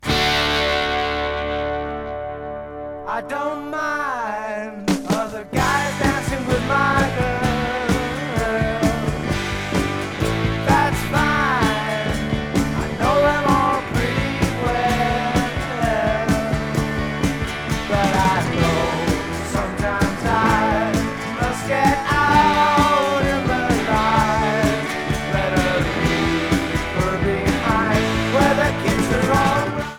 1975 USA LP